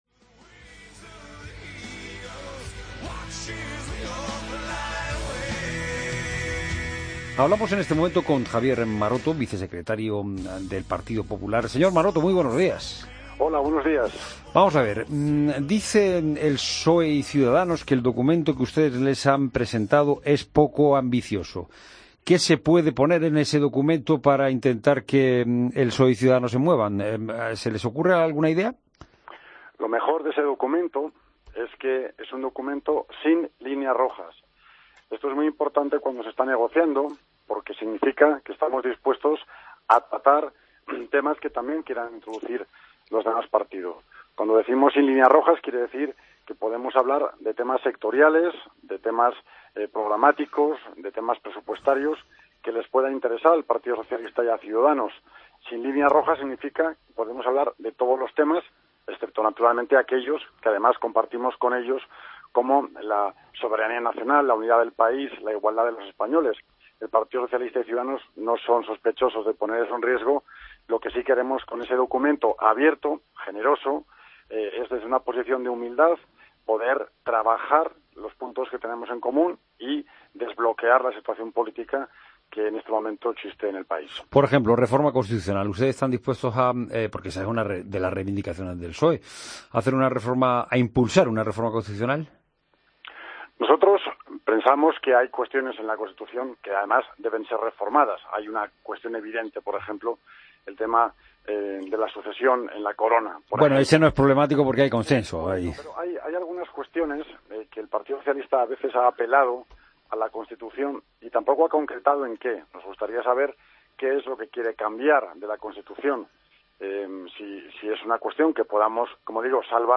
AUDIO: Entrevista a Javier Maroto, vicesecretario del PP